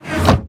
Minecraft Version Minecraft Version 1.21.5 Latest Release | Latest Snapshot 1.21.5 / assets / minecraft / sounds / block / enderchest / close.ogg Compare With Compare With Latest Release | Latest Snapshot
close.ogg